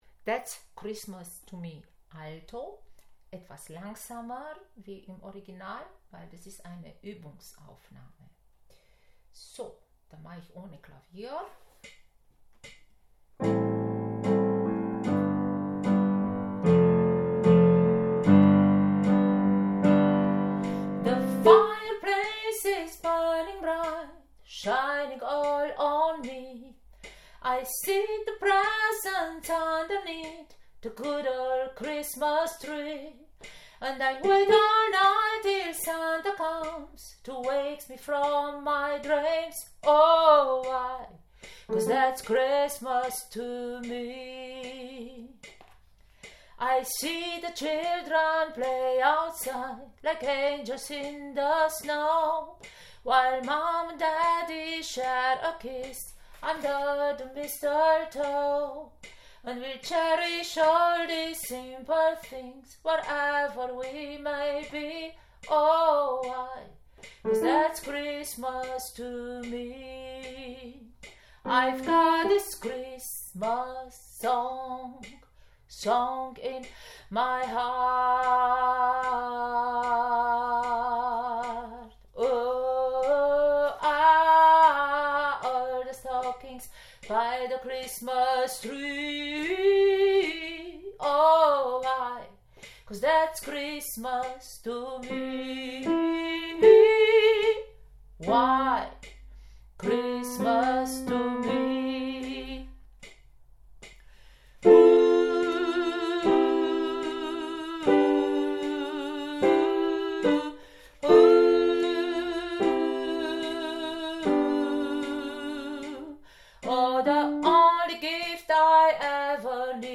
That´s-Christmas-to-me-Alto.mp3